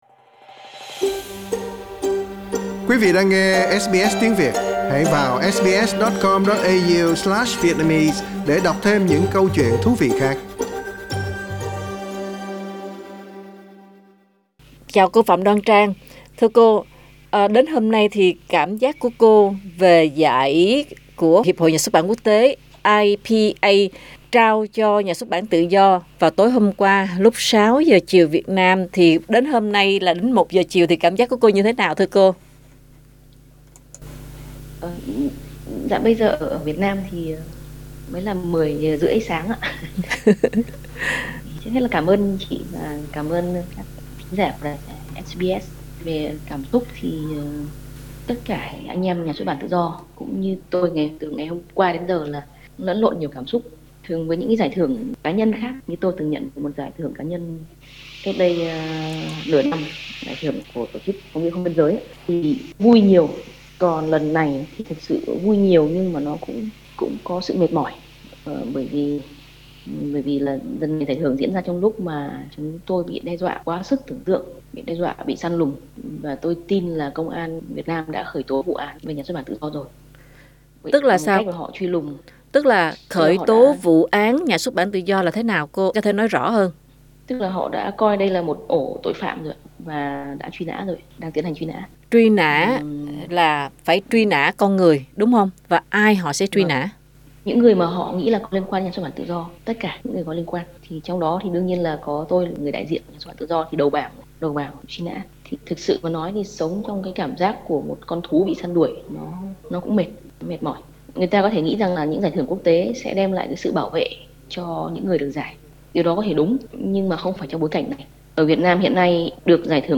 Nhà báo độc lập Phạm Đoan Trang từ NXB Tự Do cho biết cô và người của NXB đã dự trù sẳn sàng. Cuộc trò chuyện kỳ lạ giữa SBS với người đại diện của Quán quân giải Báo chí Quốc tế không phải là niềm vui mà là những trăn trở về những việc phải hoàn thành trước khi bị bắt.